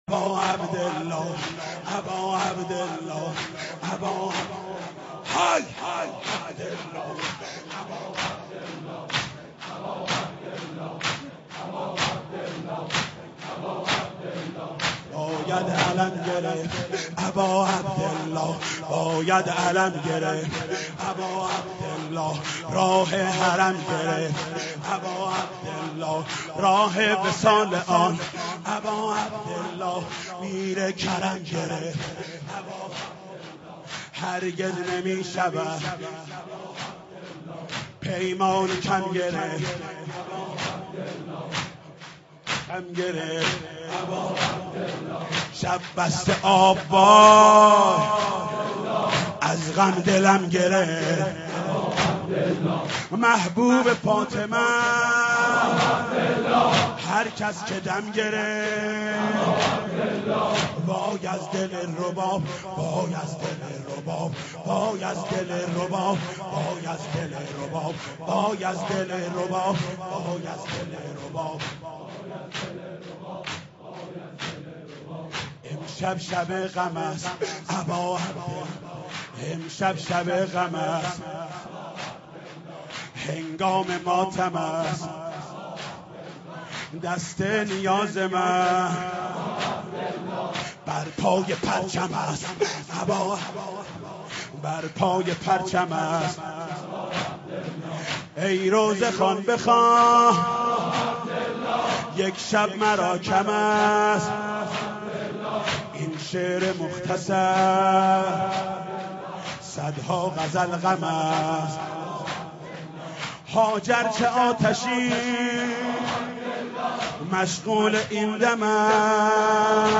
اشعار حضرت رباب سلام الله علیه به همراه سبک با صدای حاج محمود کریمی/شور -( باید علم گرفت اباعبدالله )